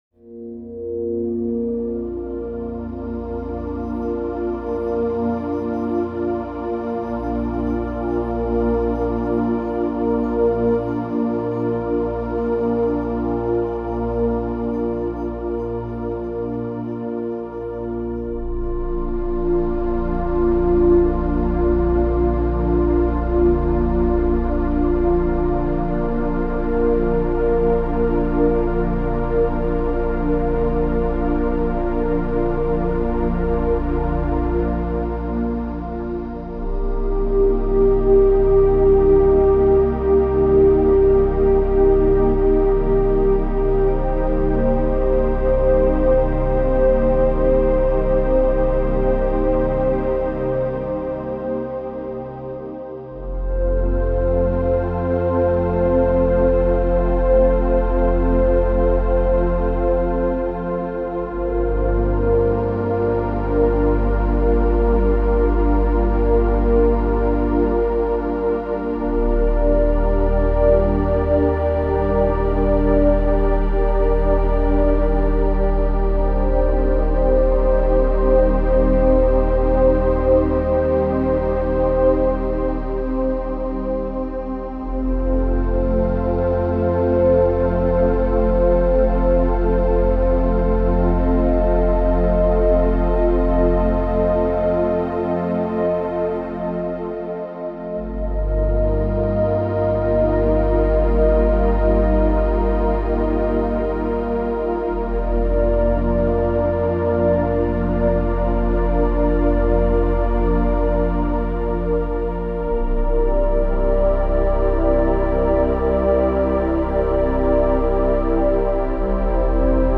Calming music for stress relief, pre-sleep, and relaxation.